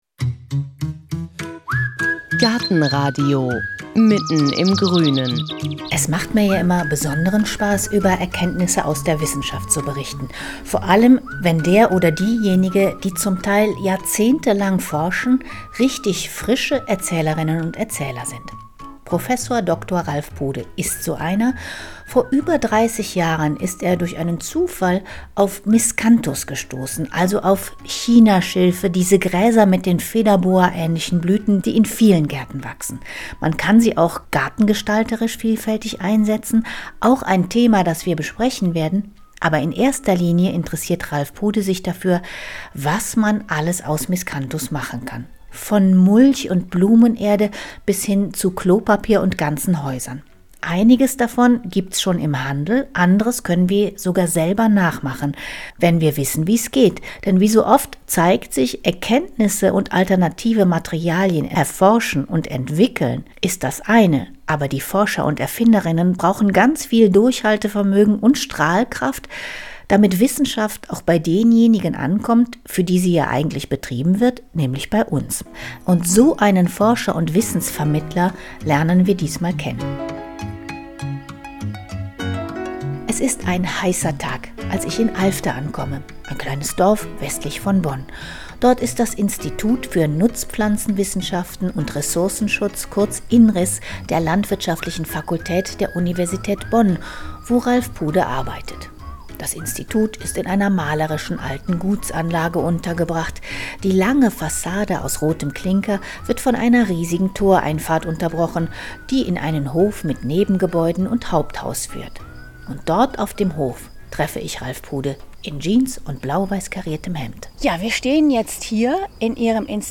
Wie er zu ihr kam, was alles dazu gehört, um aus einer Pflanze ein sinnvolles „Produkt“ zu machen, wie wir mit Chinaschilf unseren Garten gestalten und sogar zur Schneckenabwehr nutzen können – über all das sprechen wir in dieser Folge auf seinem Forschungsacker in Alfter, westlich von Bonn.